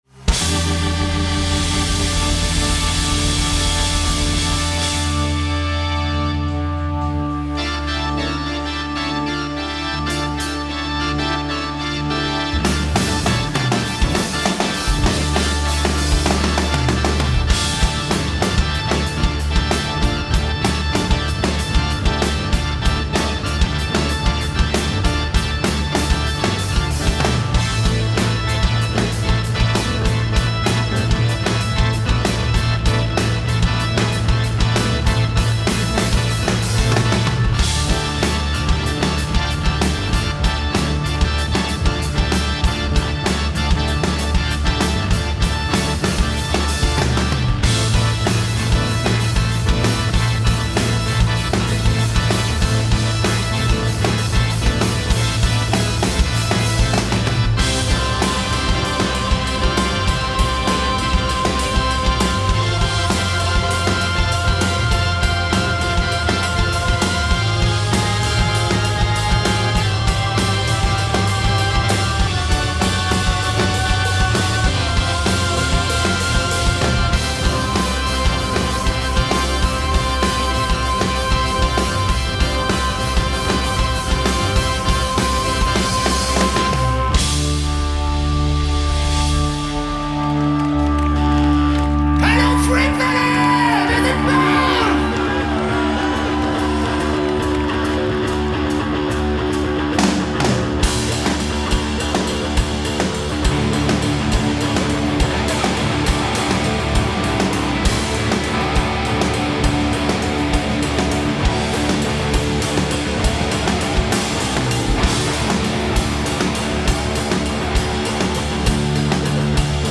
Recorded at The Freak Valley Festival
A blast of Prog
as a trio
vocals, guitars, keyboards
bass, bass synth
drums, backing vocals, keyboards
progressive rock